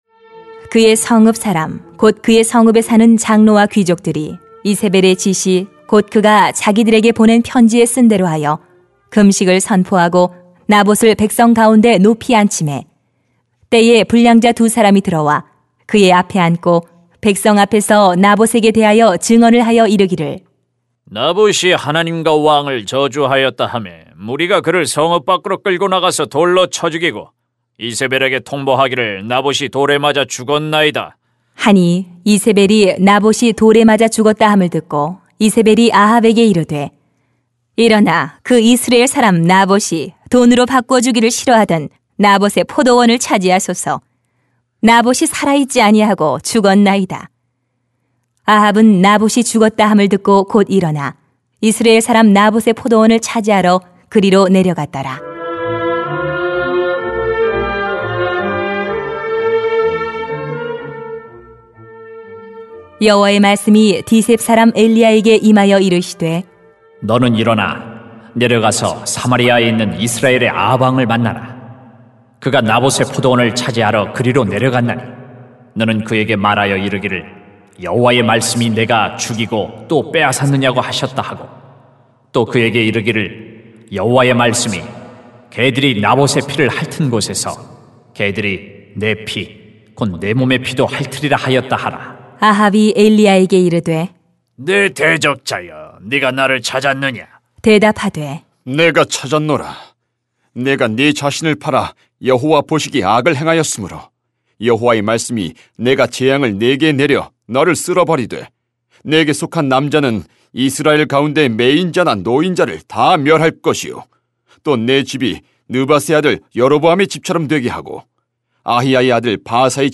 [왕상 21:11-29] 하나님이 겸비함을 주목하십니다 > 새벽기도회 | 전주제자교회